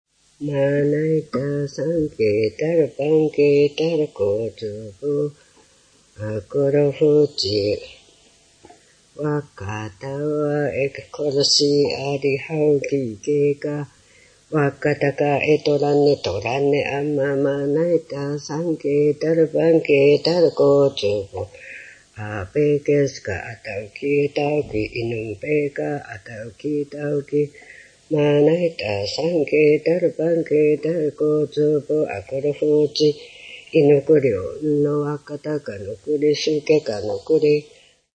• ジャンル：神謡　カムイユカㇻ／アイヌ語
• サケヘ（リフレイン）：マナイタサンケ　タㇻパンケ　タラコチュプ